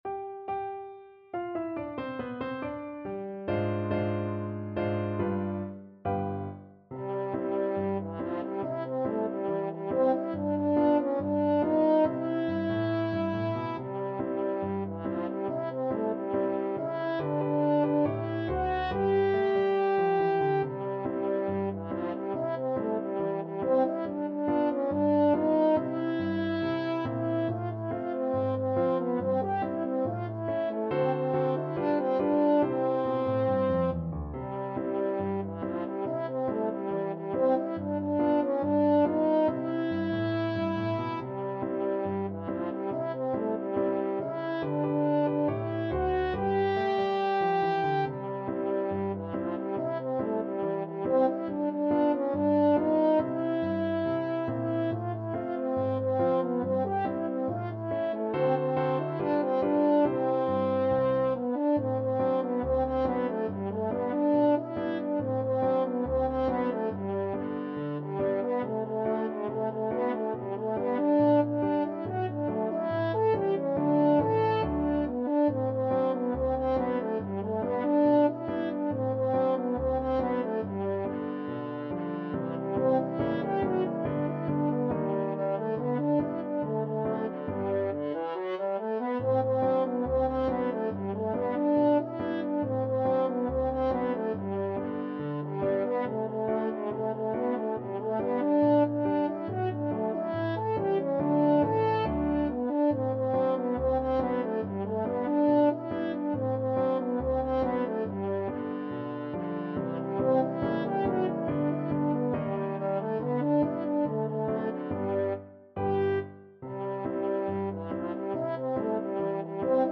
French Horn
C major (Sounding Pitch) G major (French Horn in F) (View more C major Music for French Horn )
2/4 (View more 2/4 Music)
Not fast Not fast. = 70
Jazz (View more Jazz French Horn Music)